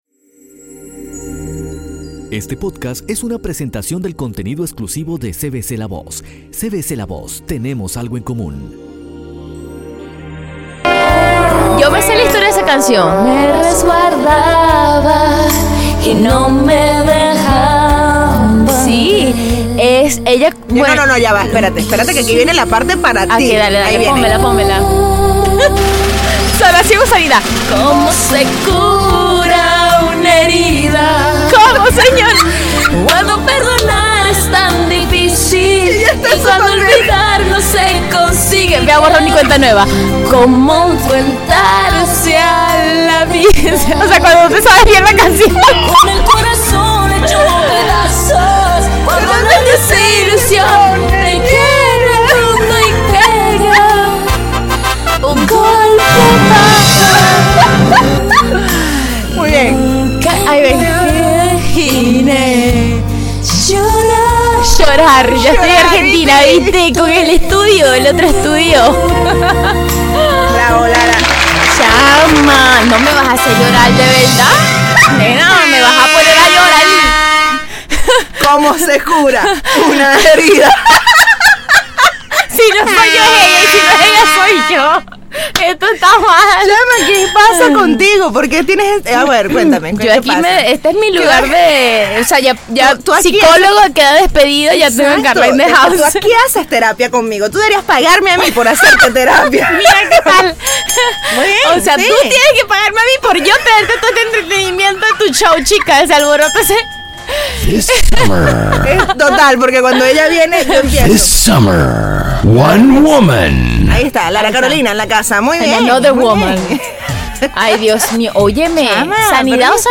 Conversaciones random